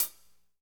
HAT P C C0IR.wav